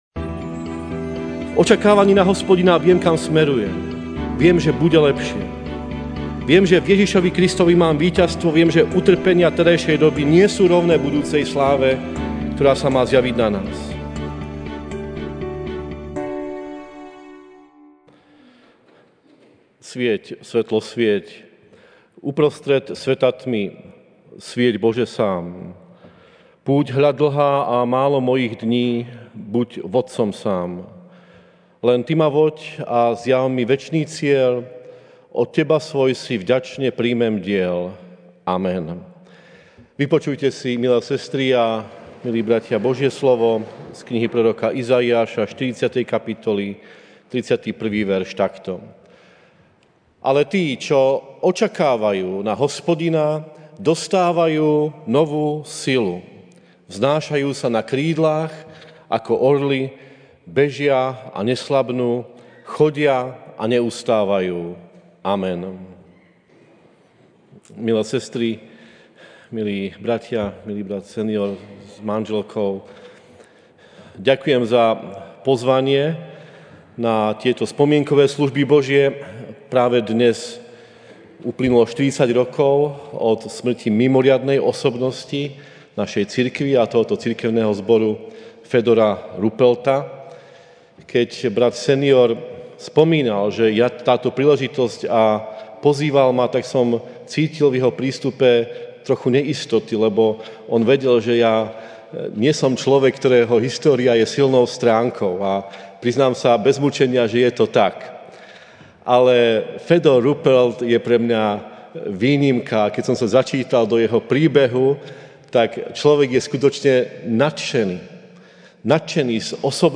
aug 25, 2019 Nezlomná viera MP3 SUBSCRIBE on iTunes(Podcast) Notes Sermons in this Series Ranná kázeň: Nezlomná viera (Iz 40, 31) Ale tí, čo očakávajú Hospodina, dostávajú novú silu, vznášajú sa na krídlach ako orly, bežia, a neslabnú, chodia, a neustávajú.